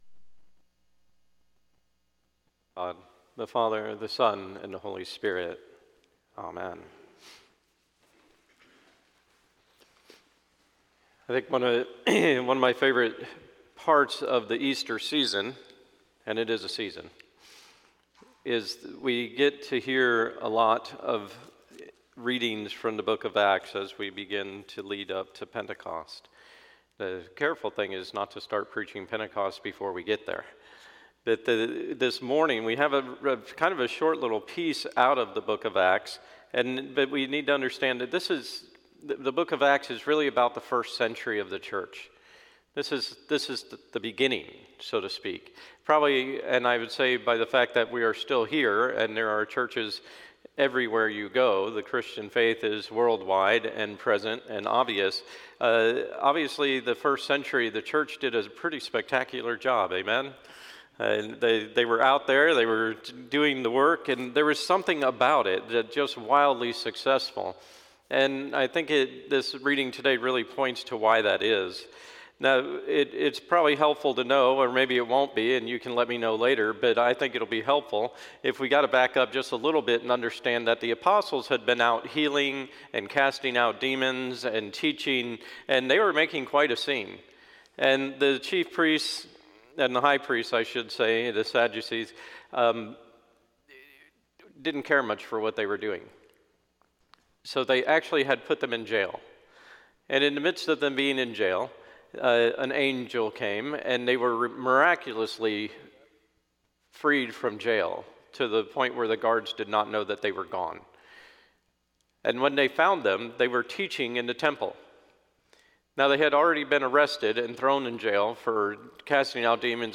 Sermon: 4/24/22 – Second Sunday of Easter